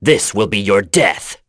Lusikiel-Vox_Skill2.wav